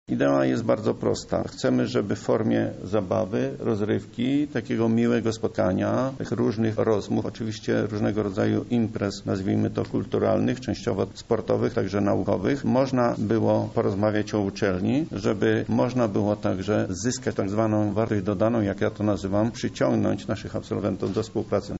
O idei zjazdu mówi prof. Stanisław Michałowski, rektor Uniwersytetu Marii Curie Skłodowskiej